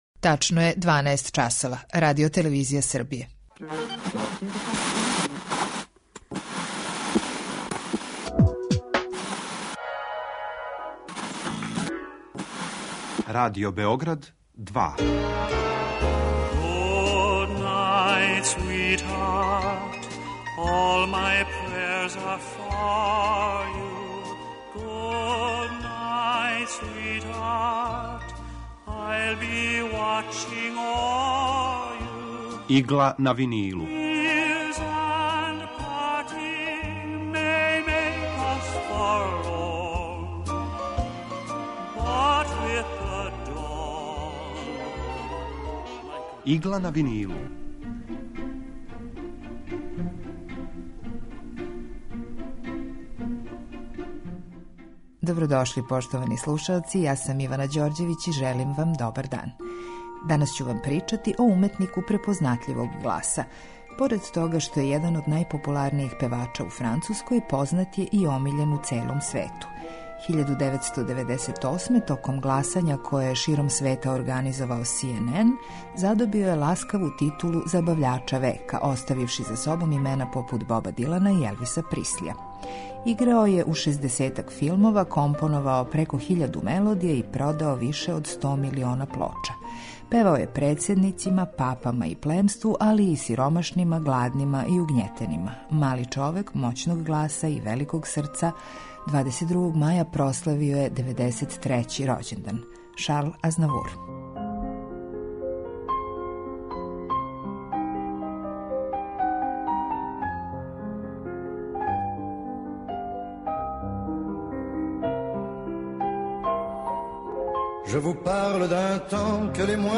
У Игли на винилу представљамо одабране композиције евергрин музике од краја 40-их до краја 70-их година 20. века. Свака емисија садржи кроки композитора / извођача и рубрику Два лица једног хита (две верзије исте композиције)